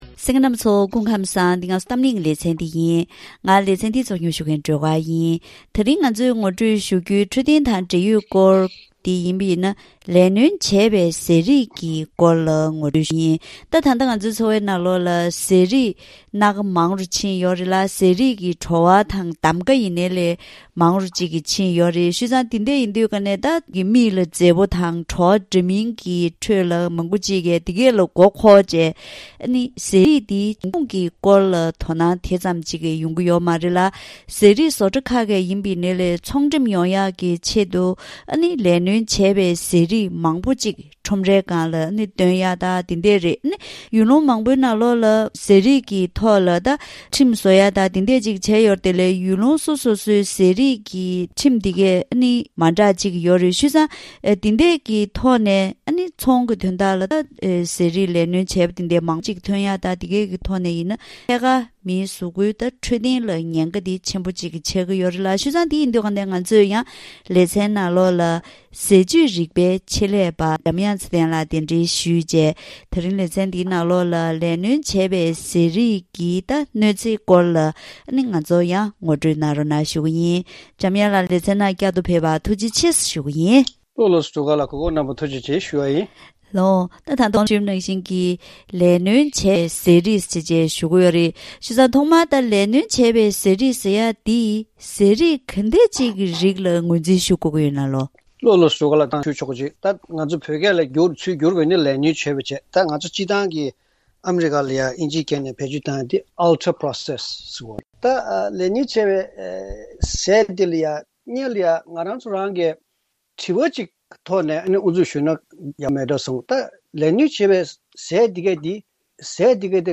ལེ་ཚན་ནང་ཟས་བཅུད་རིག་པའི་ཆེད་ལས་པ་དང་ལྷན་དུ་བཀའ་མོལ་ཞུས་པ་ཞིག་གསན་རོགས་གནང་།